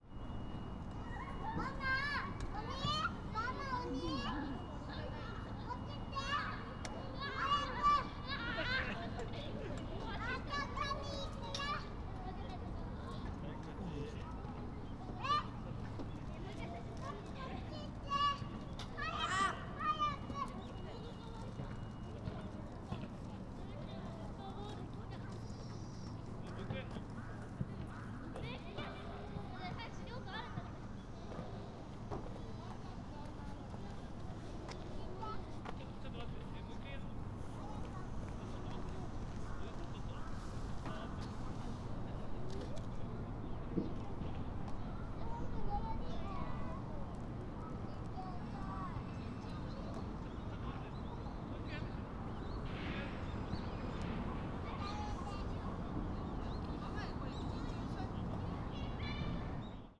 Fukushima Soundscape: Shinhama Park